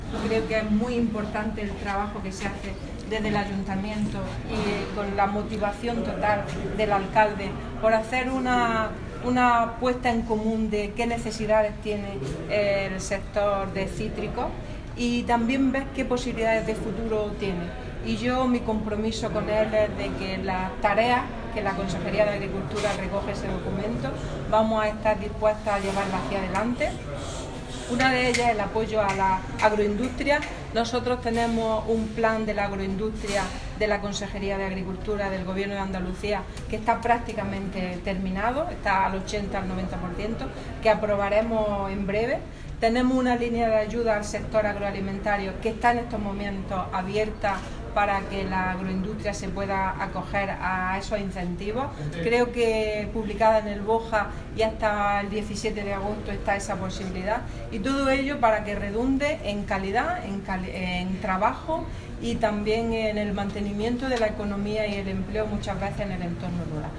Declaraciones de Carmen Ortiz sobre apoyo de la Consejería al sector de los cítricos